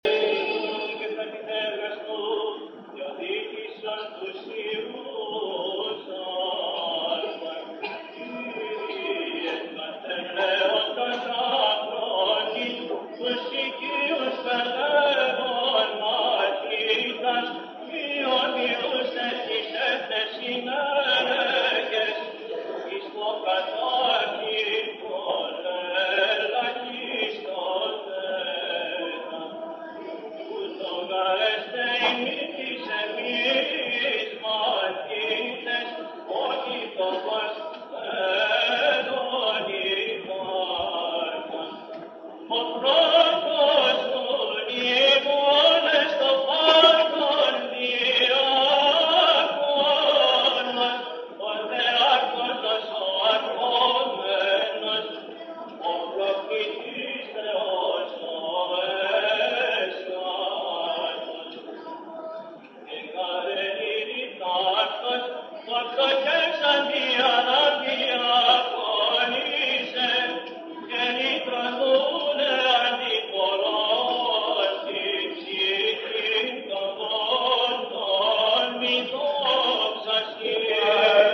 (ἠχογρ. Κυρ. Βαΐων ἑσπέρας)